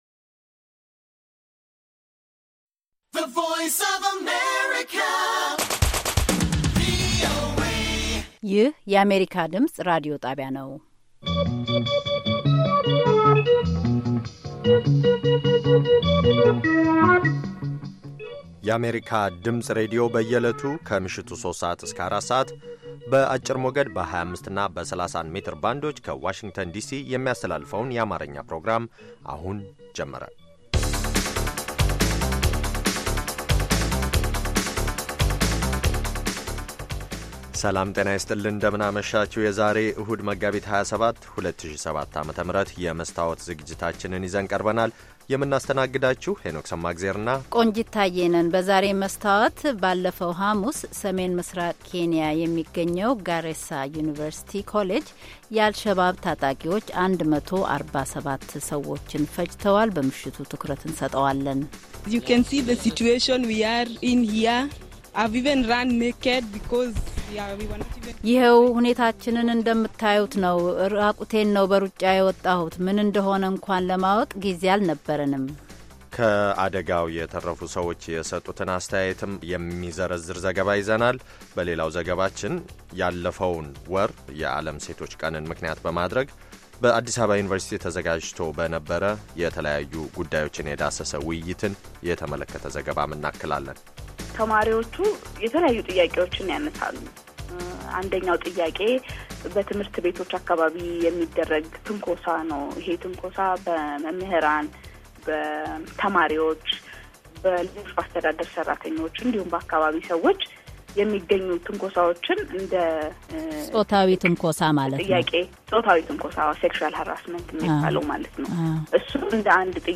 ከምሽቱ ሦስት ሰዓት የአማርኛ ዜና